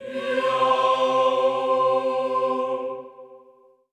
HEE-AH  C3-L.wav